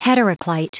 Prononciation du mot heteroclite en anglais (fichier audio)
Prononciation du mot : heteroclite